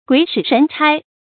guǐ shǐ shén chāi
鬼使神差发音
成语正音 差，不能读作“chà”。